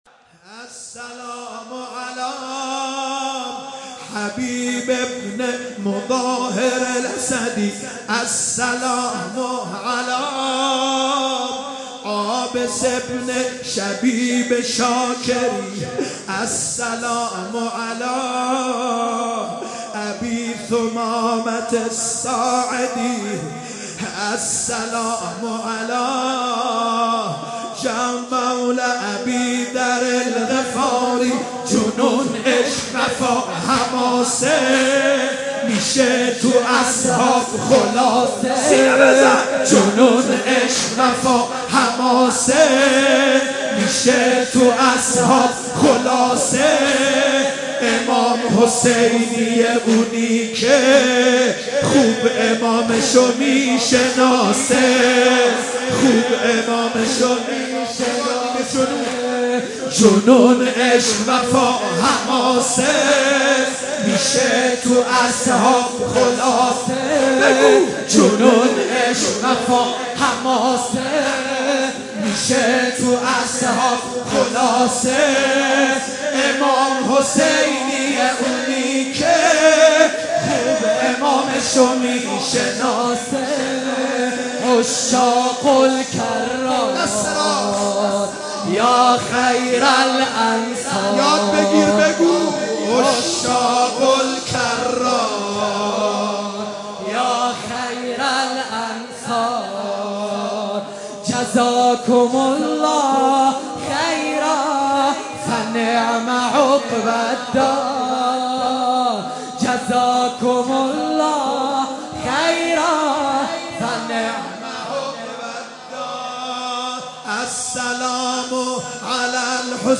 مداحی محرم
السَّلامُ عَلی حَبیبِ بنِ مُظاهِر اَسَدی _ رجز خوانی سيد مهدی ميرداماد شب چهارم محرم 96/07/2